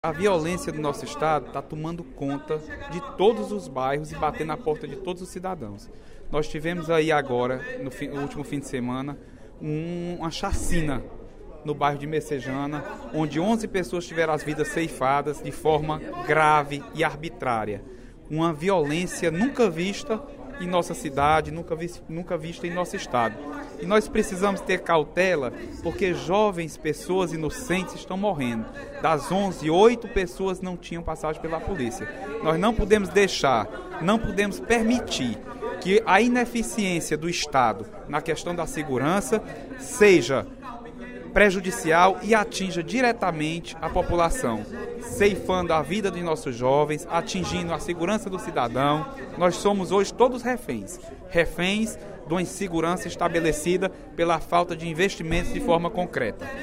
O deputado Leonardo Araújo (PMDB) criticou, no primeiro expediente da sessão plenária desta quarta-feira (18/11), a insegurança no Ceará e lamentou as mortes ocorridas na madrugada de quinta-feira (12/11), em Messejana.